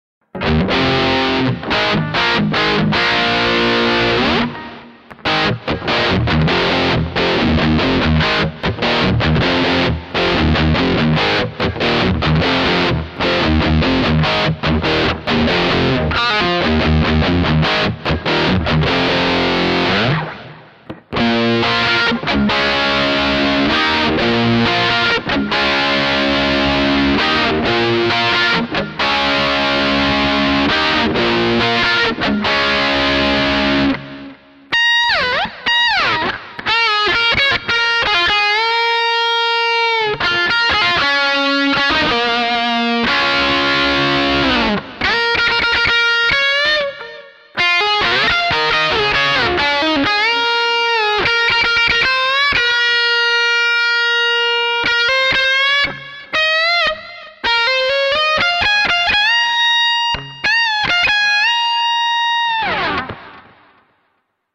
Here is another clip of a typical rock tone that I would use live. The chain is the same as before : LP > Legacy > PDI-03 > G-Major 2 > interface.